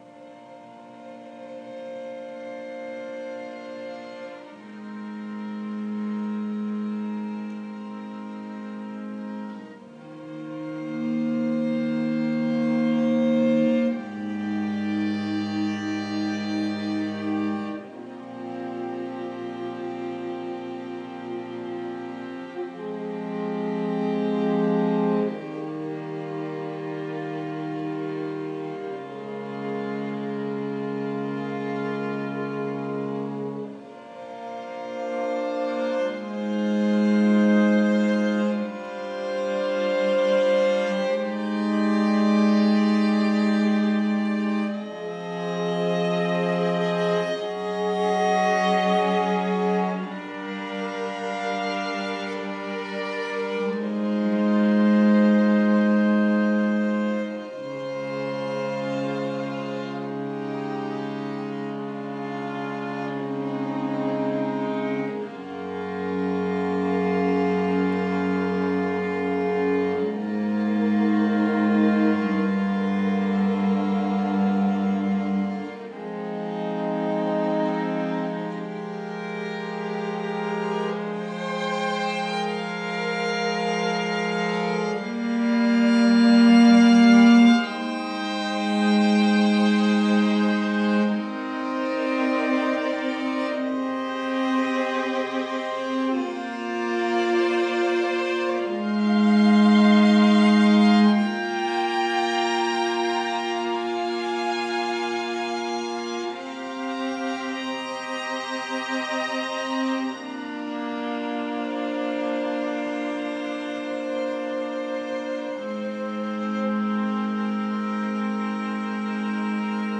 String Quartet in recording